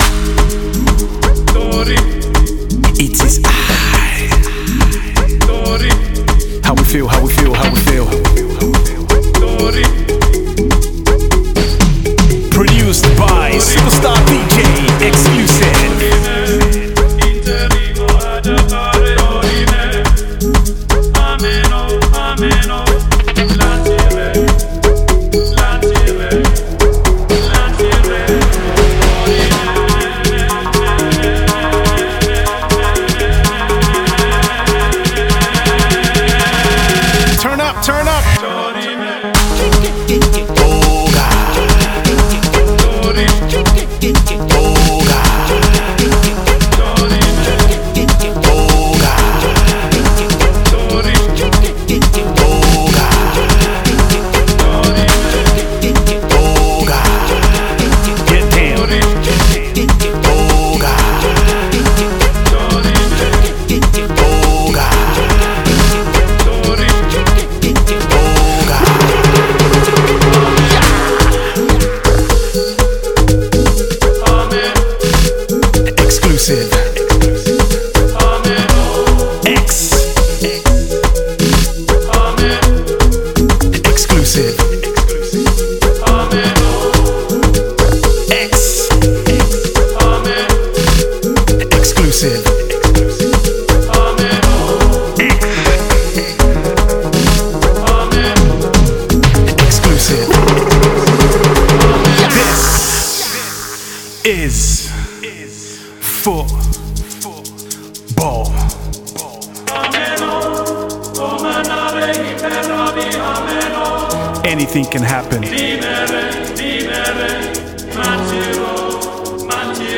refix